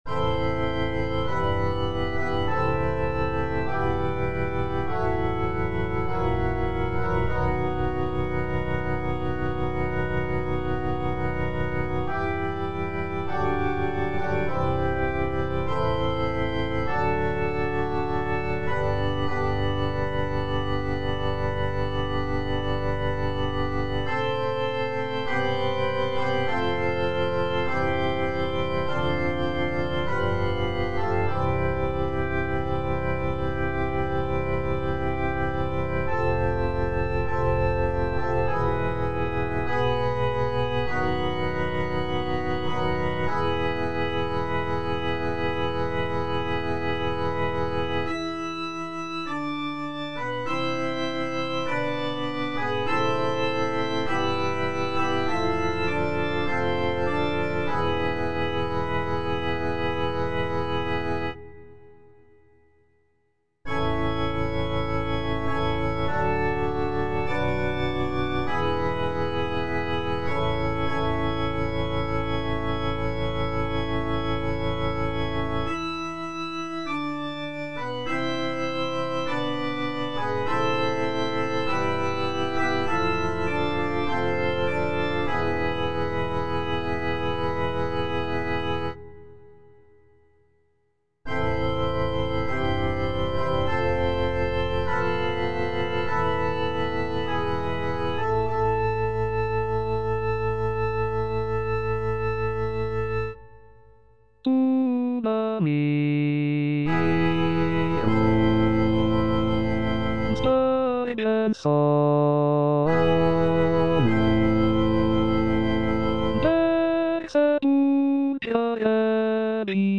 F. VON SUPPÈ - MISSA PRO DEFUNCTIS/REQUIEM Tuba mirum (alto II) (Emphasised voice and other voices) Ads stop: auto-stop